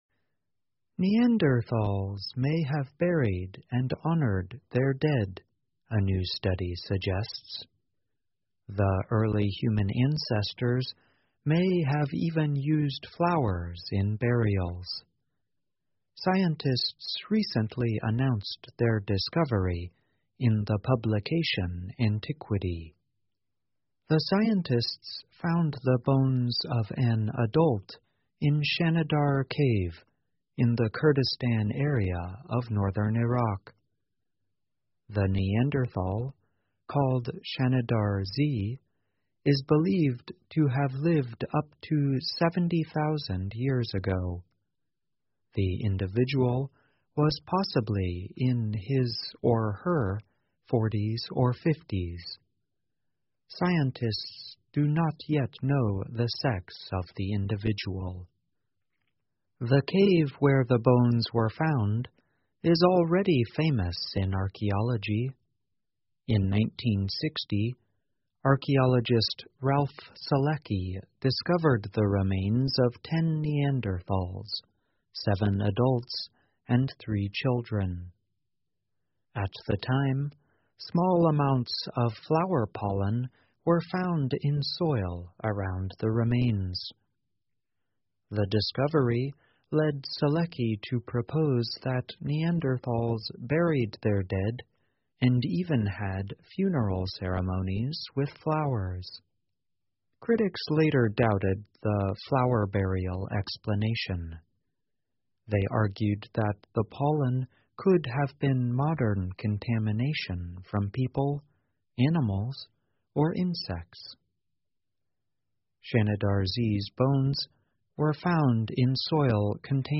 VOA慢速英语2019 新证据表明人类祖先用鲜花埋葬死者 听力文件下载—在线英语听力室